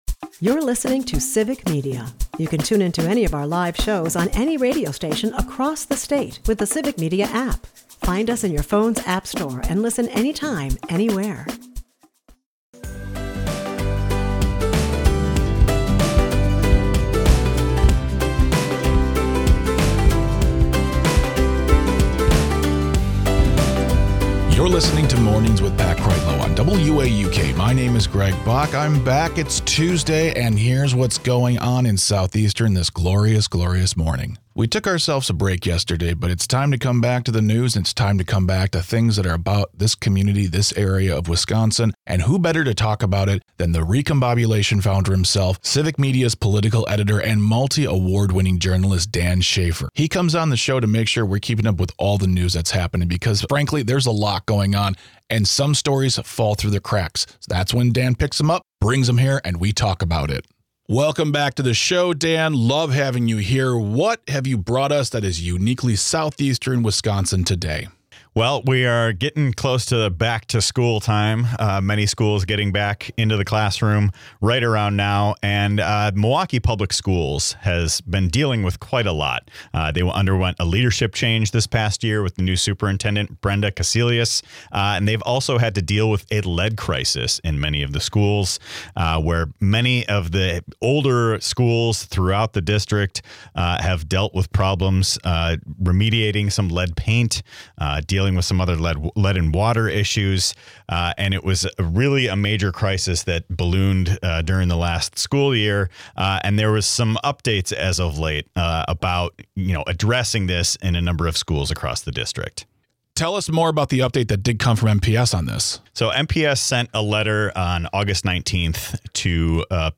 WAUK Morning Report is a part of the Civic Media radio network and air four times a morning.